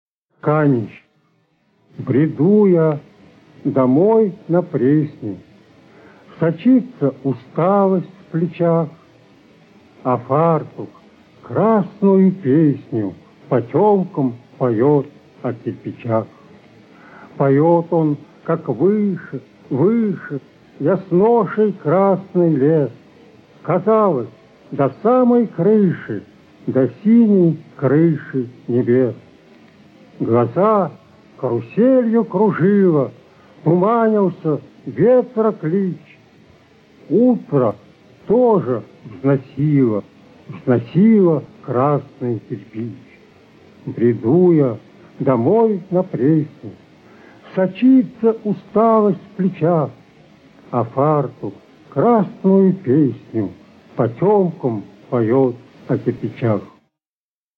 1. «Василий Казин – Каменщик (читает автор)» /